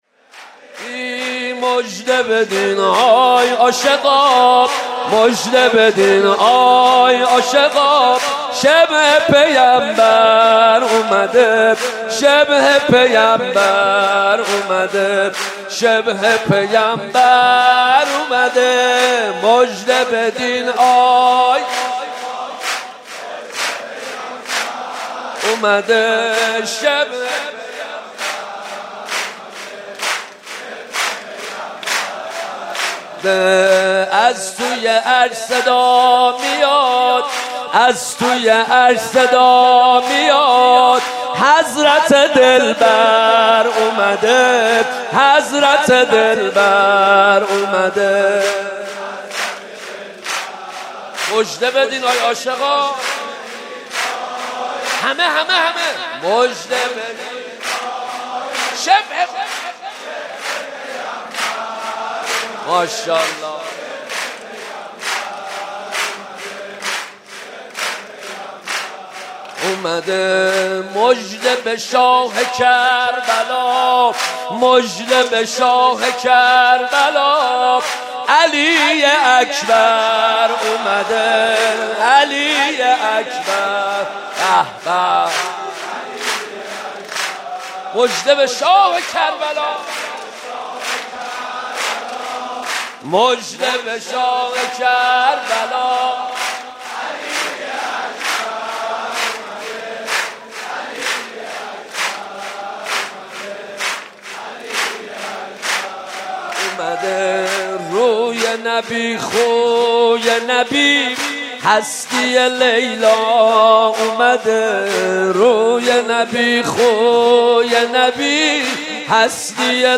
برچسب ها: گلچین مولودی